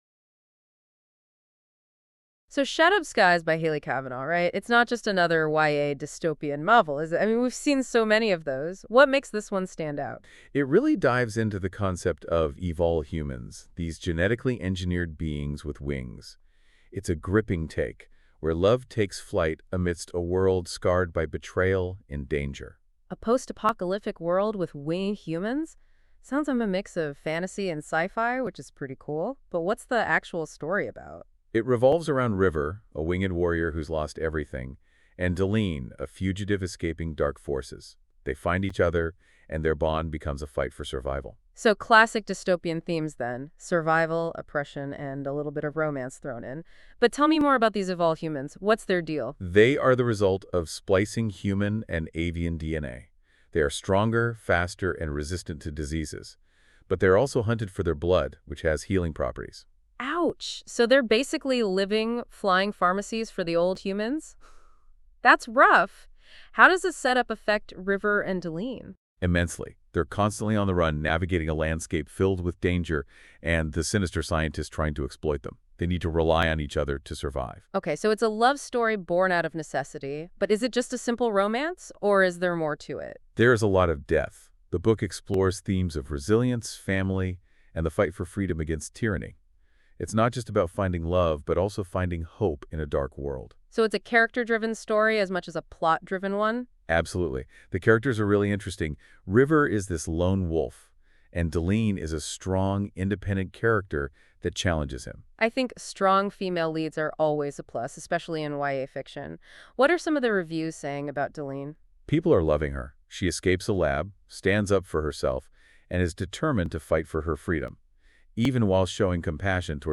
This newly released audio commercial offers a cinematic first listen,